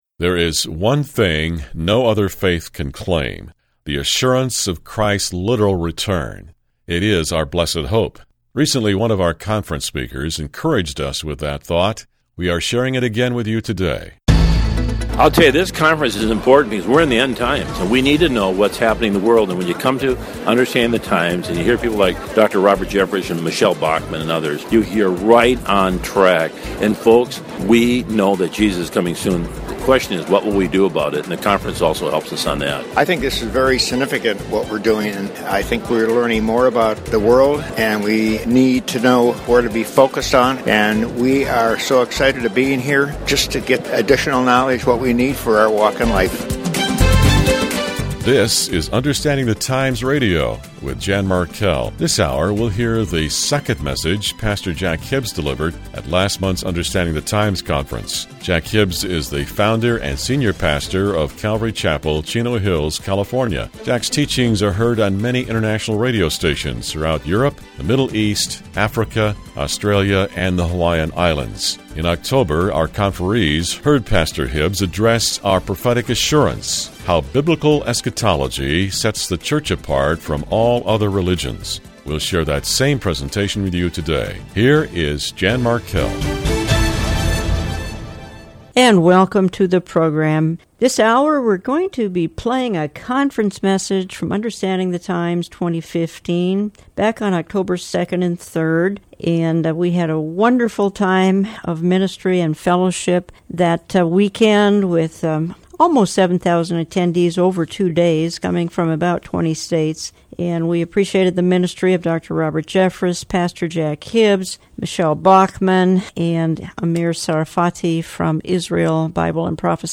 at the 2015 Understanding the Times Conference
Below is a very encouraging sermon from the Olive Tree Ministries website, out of the “Understanding the Times” conference.